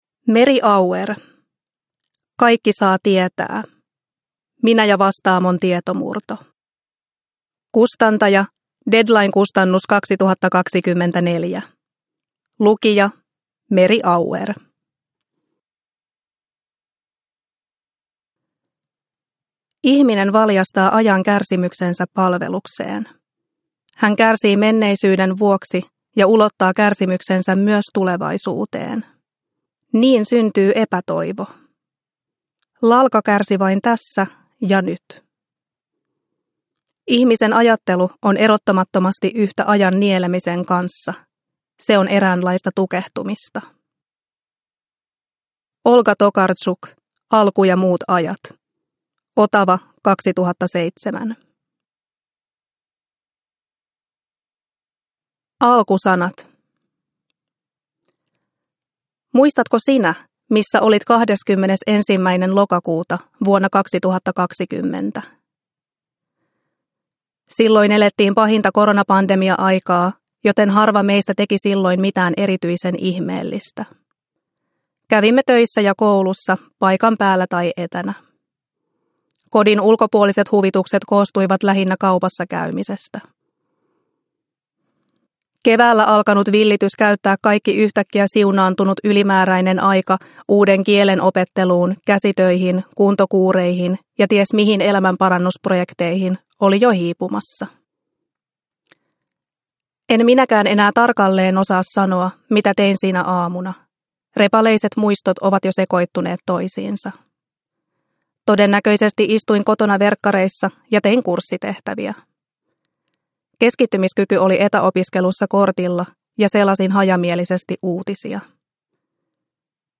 Kaikki saa tietää – Ljudbok